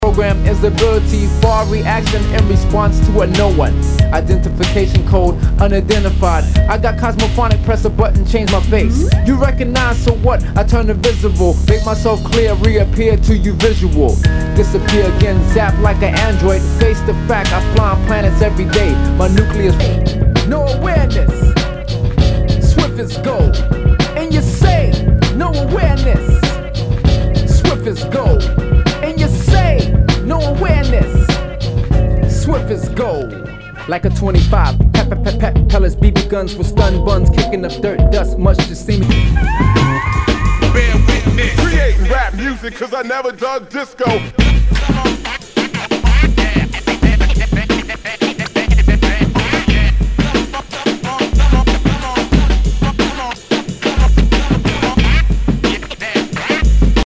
HIPHOP/R&B
ナイス！ヒップホップ / カットアップ・ブレイクビーツ！